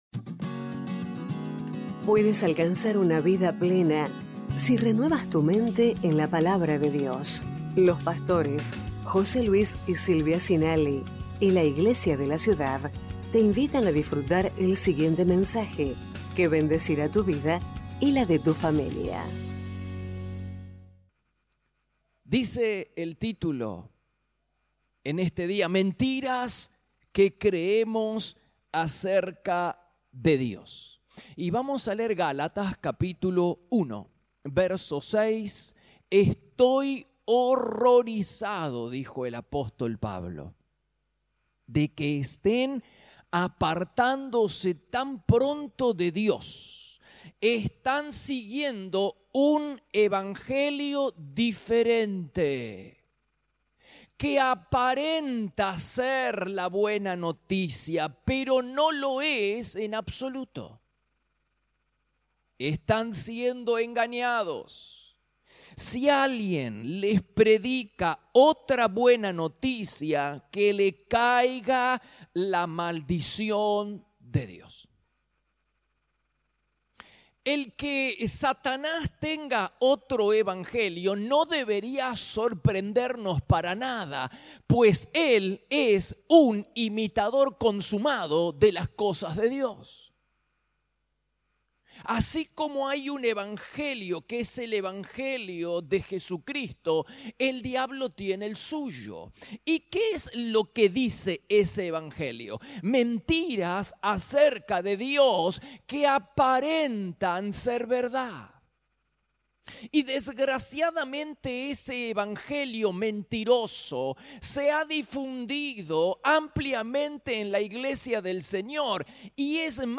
Iglesia de la Ciudad - Mensajes / Mentiras que creemos acerca de Dios 17/4/2022 #1192